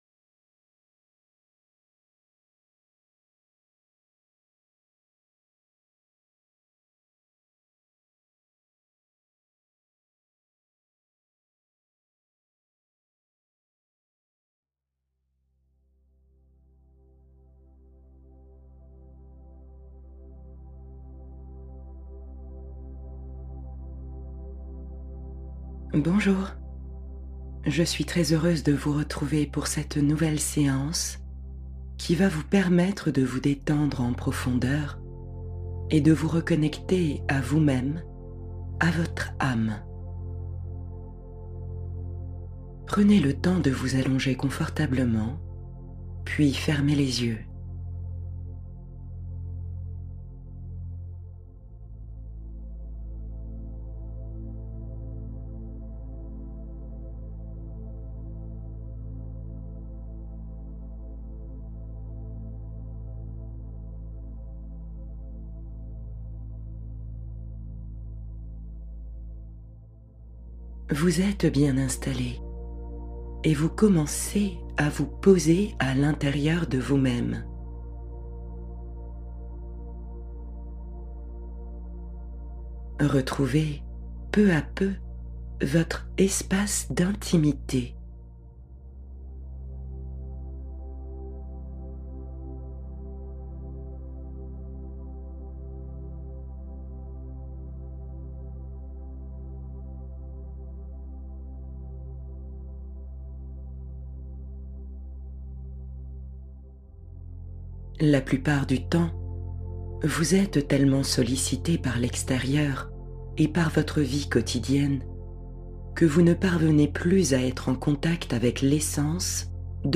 Bain de lumière blanche — Rituel guidé d’apaisement intérieur